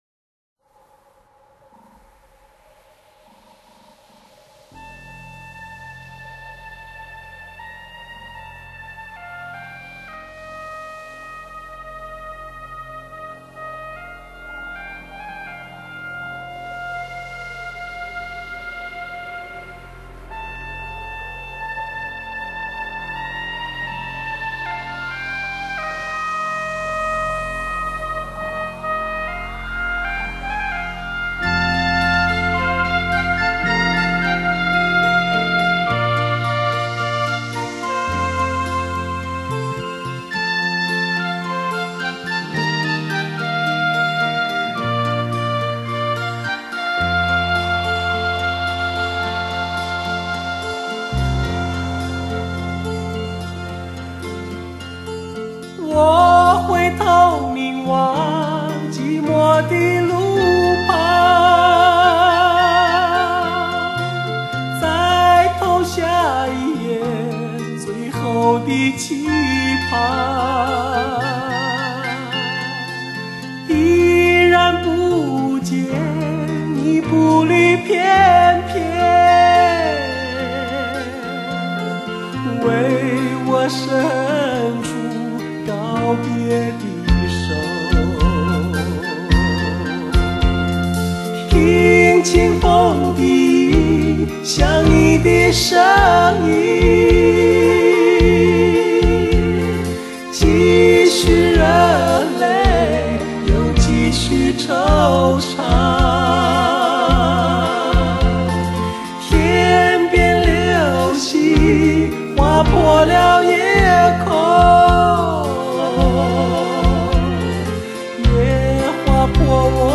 其实不止外表，连声音也会和现在的温圆甜润有些区别，可以说是带着一些未确定的青涩，所以更加难得。